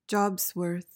PRONUNCIATION:
(JOBZ-wuhrth)